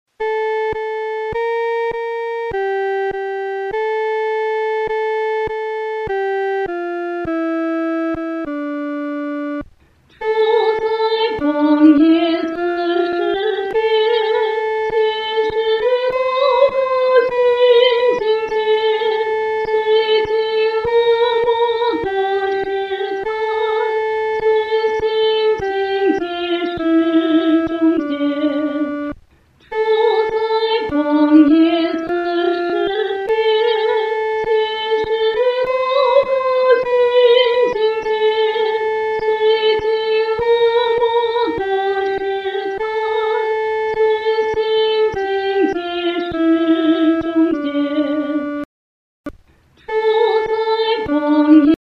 合唱
女高
本首圣诗由网上圣诗班 (石家庄一组）录制
这首诗歌宜用不太慢的中速弹唱。